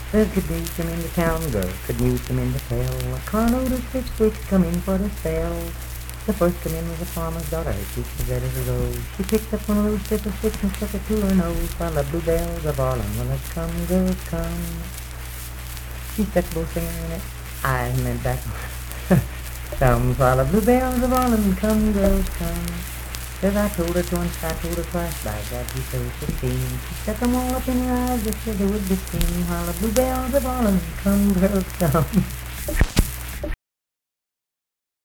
Unaccompanied vocal music performance
Bawdy Songs
Voice (sung)
Braxton County (W. Va.)